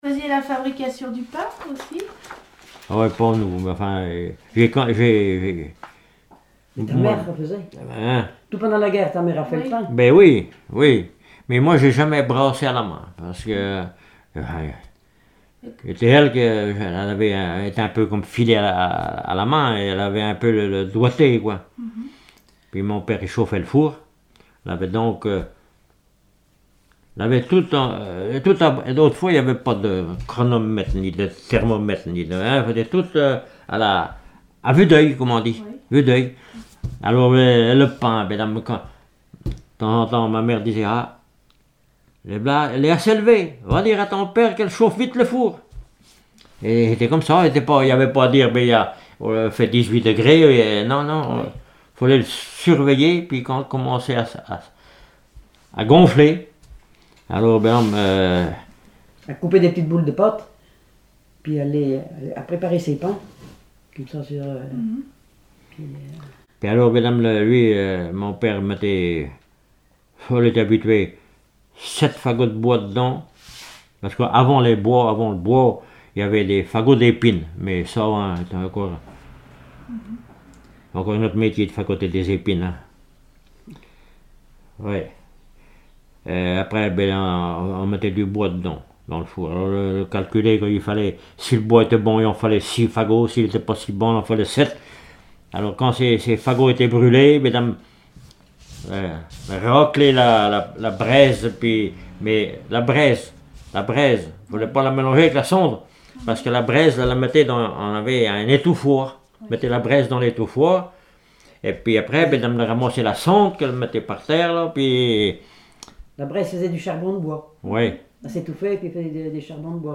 témoignages
Catégorie Témoignage